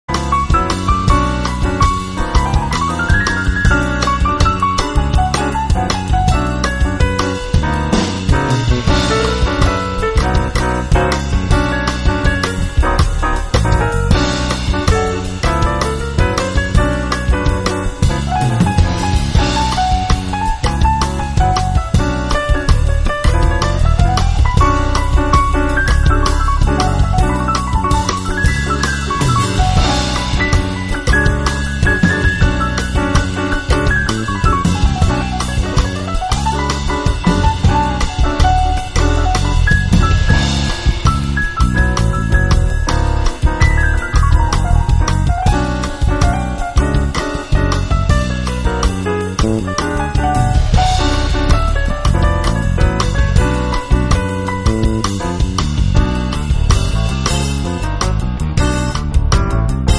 drums, synthesizers, piano
bass
electric guitar